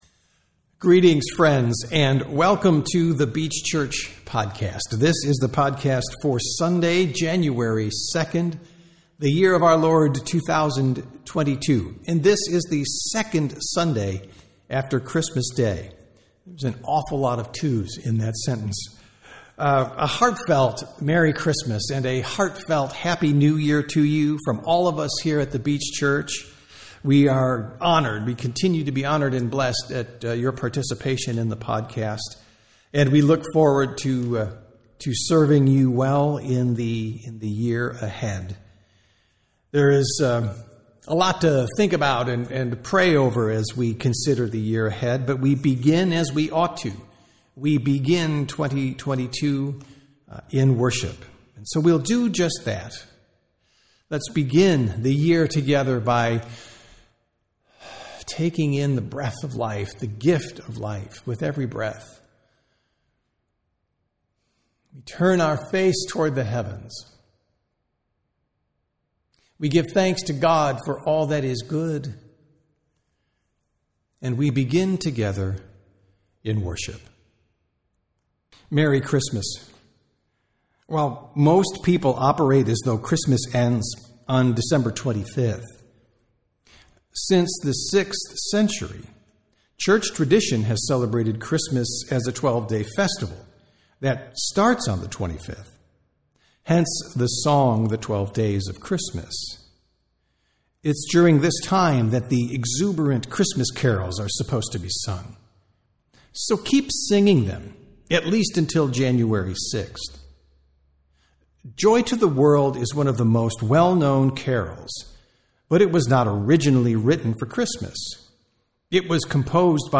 Sermons | The Beach Church
Sunday Worship - January 1, 2023